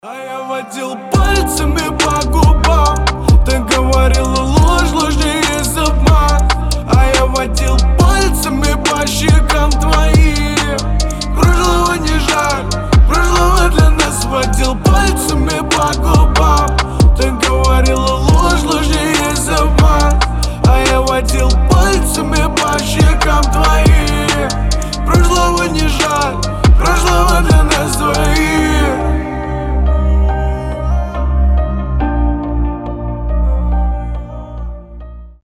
• Качество: 320, Stereo
мужской вокал
лирика
грустные
пианино